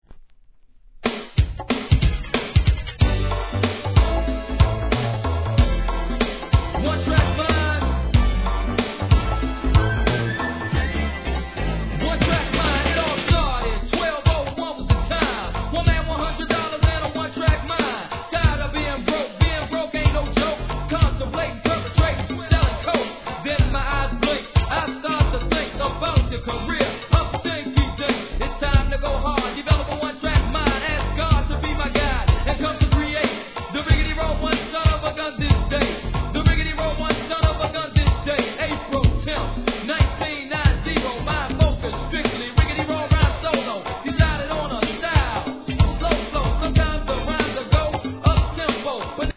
HIP HOP/R&B
美メロなサビがNEW JACK SWINGファンにもお勧めできるFUNKYミドル!!